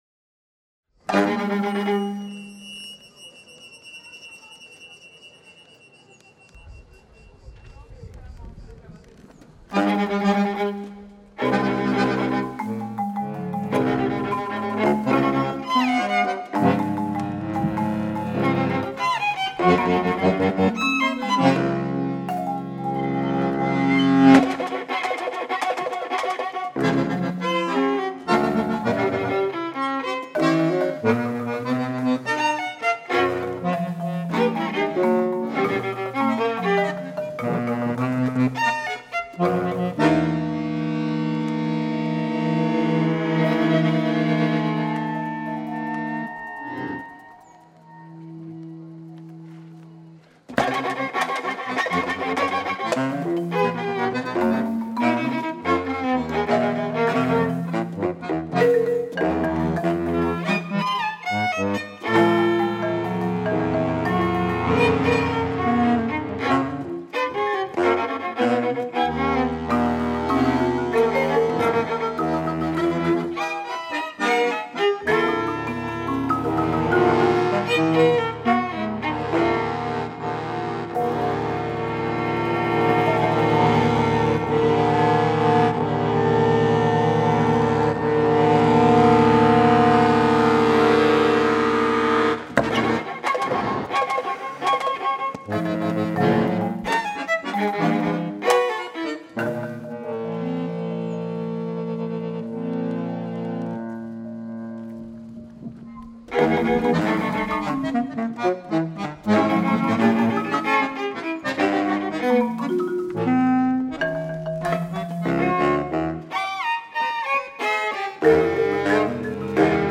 meditative and intense. https